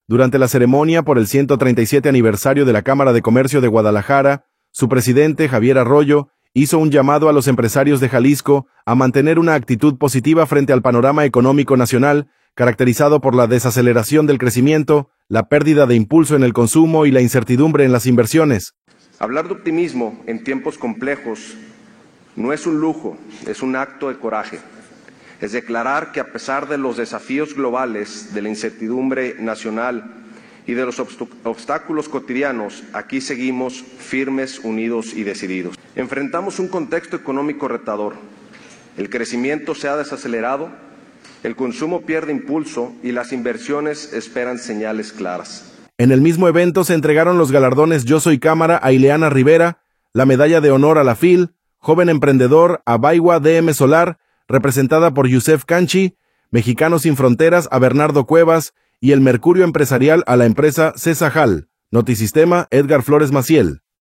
Durante la ceremonia por el 137 aniversario de la Cámara de Comercio de Guadalajara